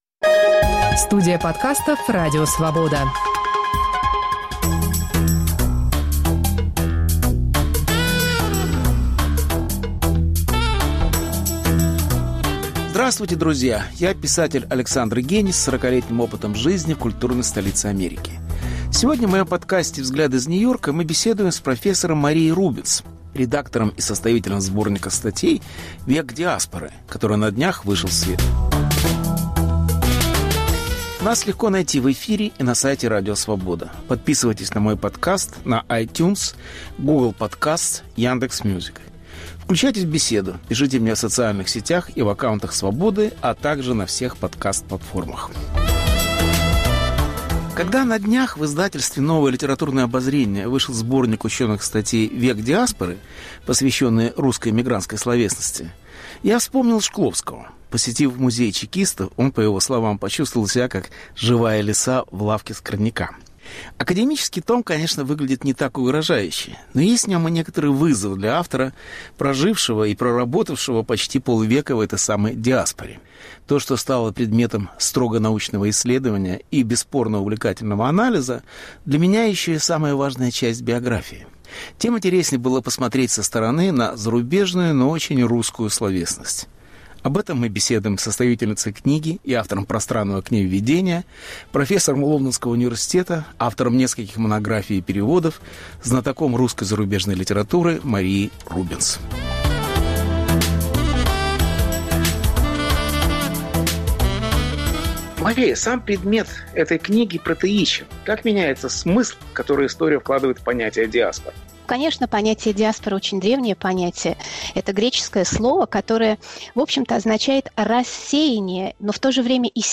Повтор эфира от 26 апреля 2021 года.